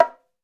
Index of /musicradar/essential-drumkit-samples/Hand Drums Kit
Hand Bongo 01.wav